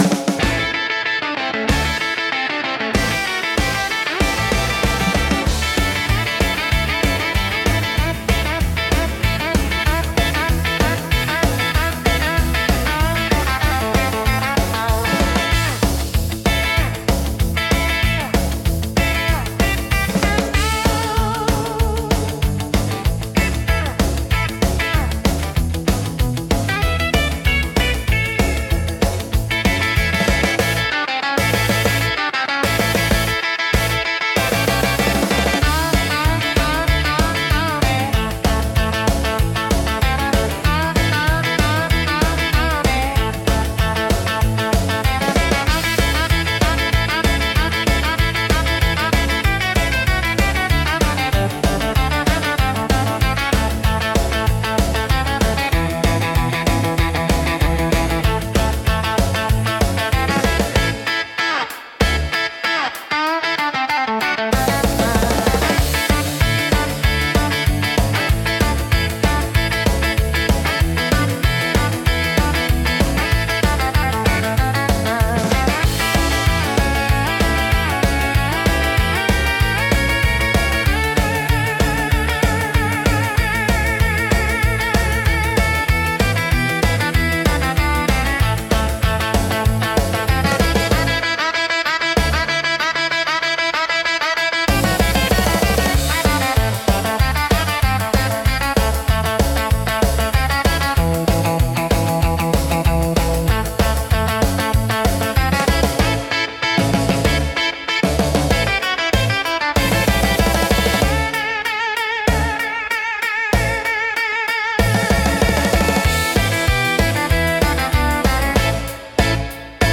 懐かしさと若々しいエネルギーが共存し、楽しい空気づくりに効果的なジャンルです。